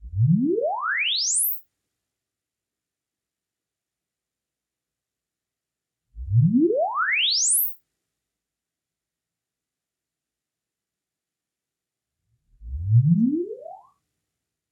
sweeps
à la maison - Neumann MA-1 / FF400
calibration.mp3